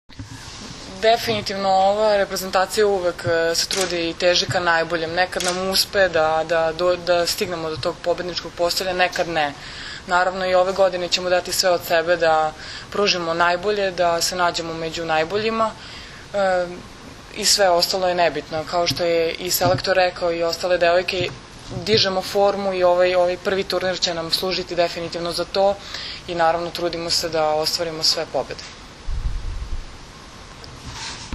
danas je u beogradskom hotelu “M” održana konferencija za novinare kojoj su prisustvovali Zoran Terzić
IZJAVA